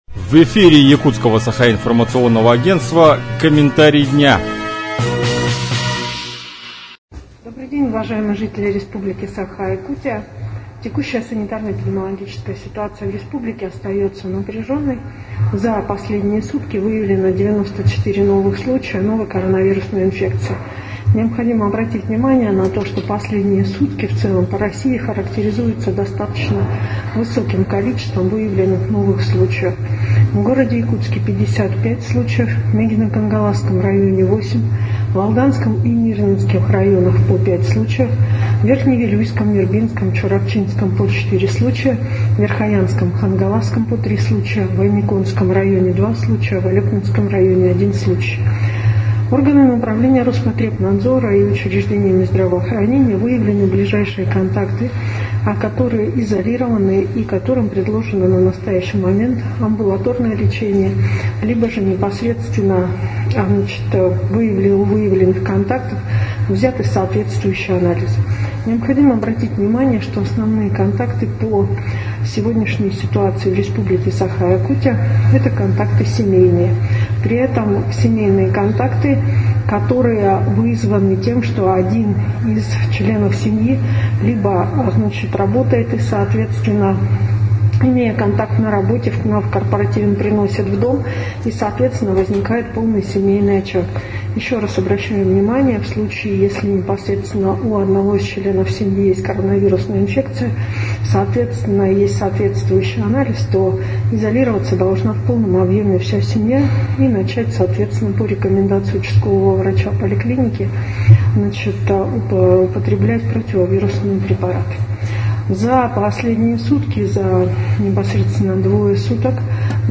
Про обстановку в Якутии на 5 октября рассказала вице-премьер Якутии Ольга Балабкина.